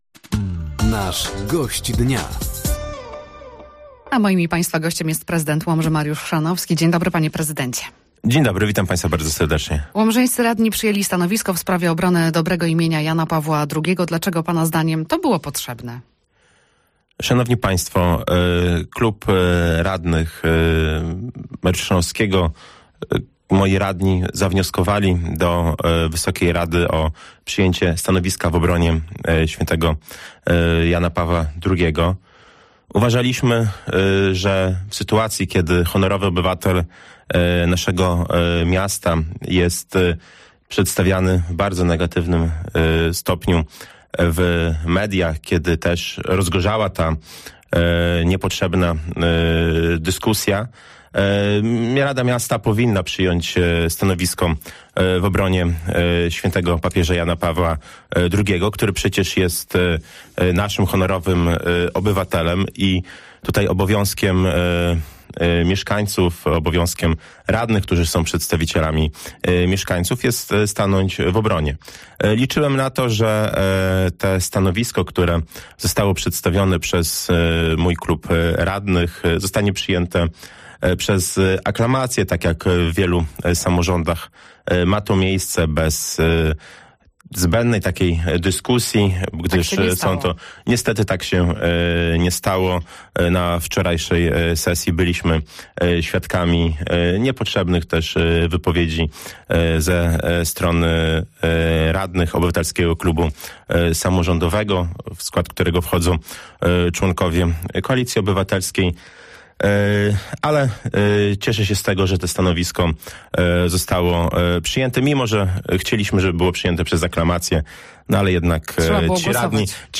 Gościem Dnia Radia Nadzieja był Mariusz Chrzanowski, Prezydent Łomży.